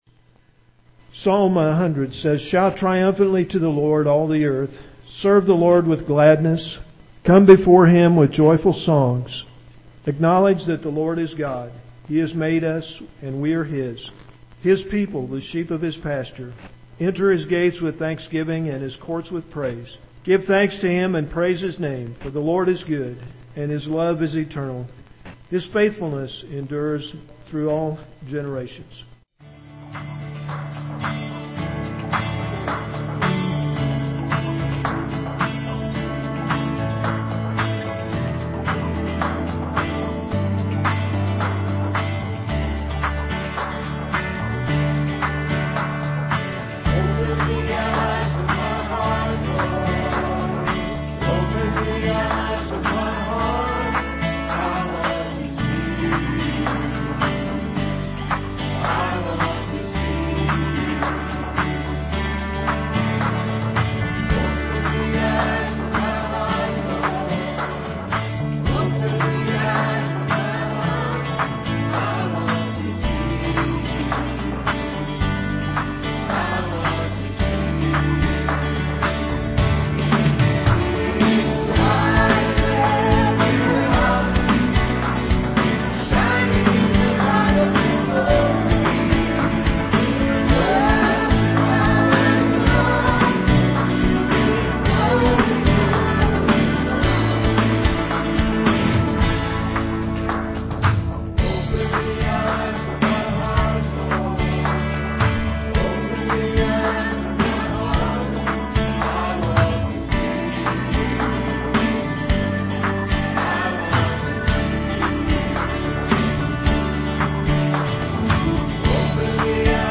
Fifth Sunday Night Sing
"Give Thanks" piano / violin duet
guitar and vocal solo
"New Creation Today" (vocal and guitar).
(violin solo)